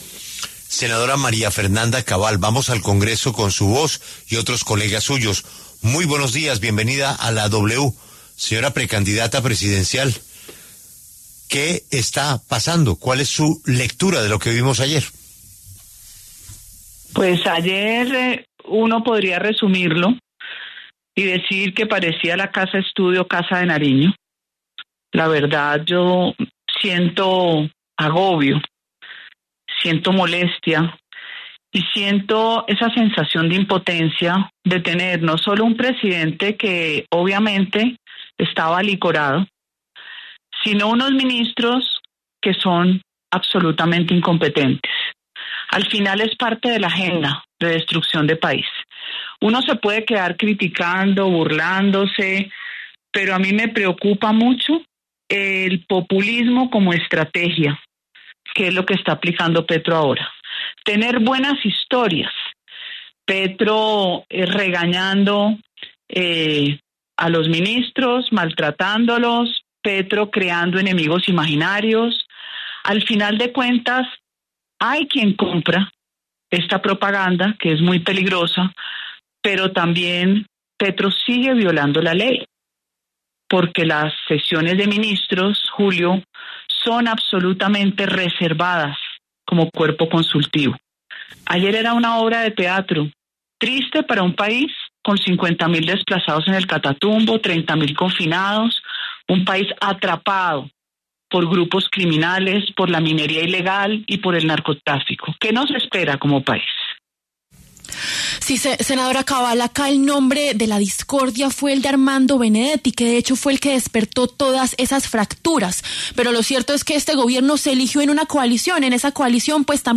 La senadora María Fernanda Cabal, del Centro Democrático, y el representante Heráclito Landinez, del Pacto Histórico, pasaron por los micrófonos de La W.